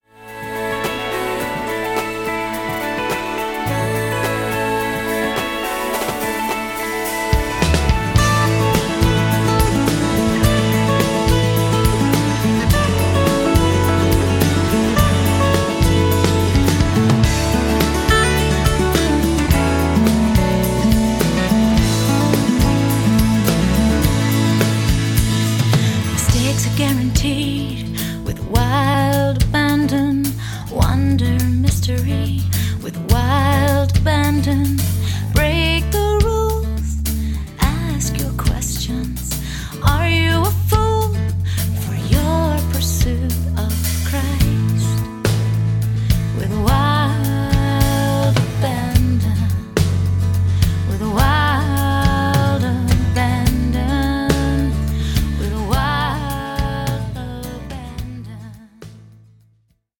Verpackt in leichtfüßige Popmusik mit Celtic-Folk-Einflüssen